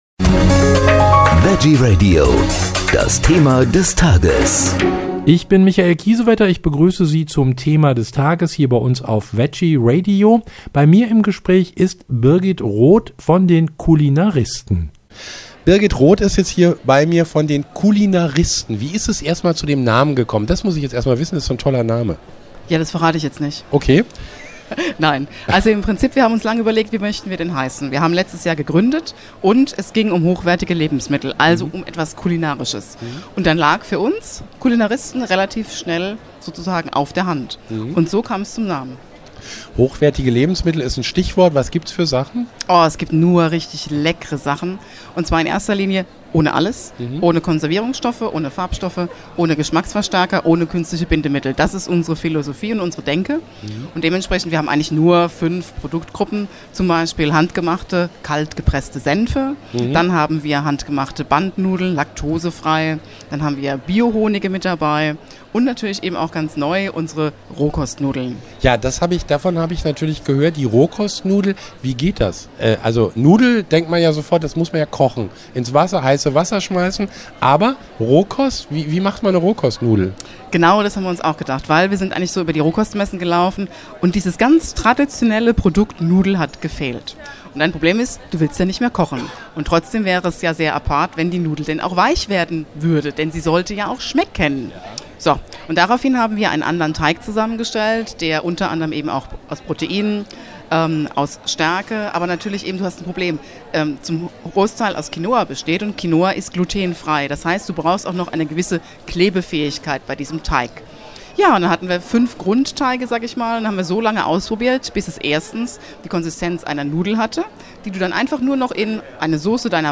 Thema des Tages 14.04.14 Kulinaristen Gespräch